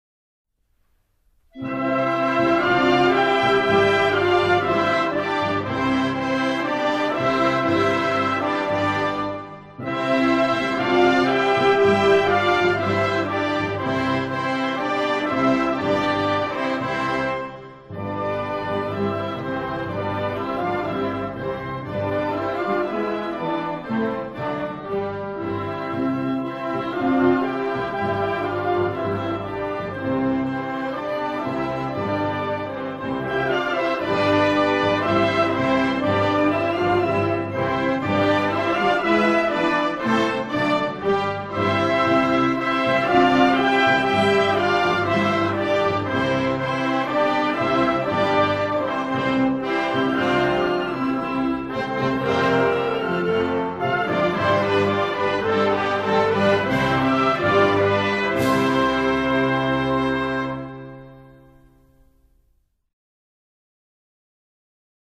Hymne Européen
02-Hymne-Europe.mp3